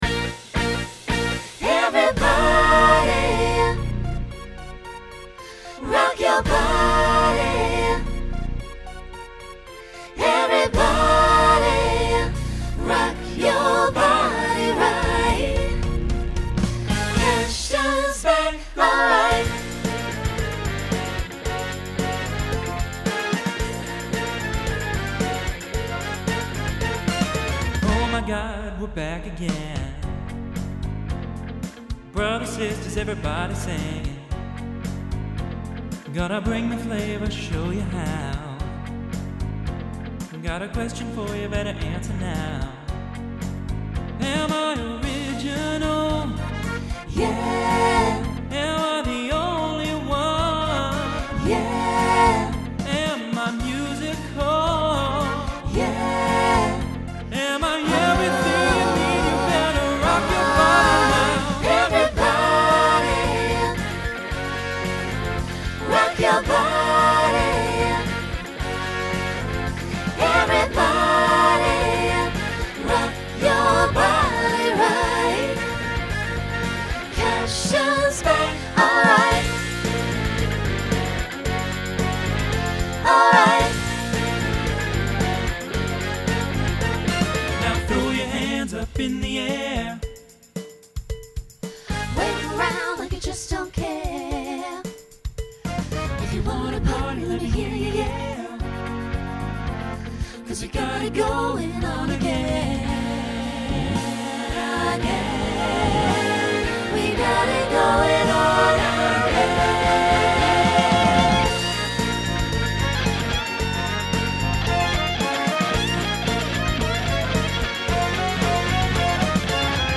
New TTB voicing for 2023.
Genre Pop/Dance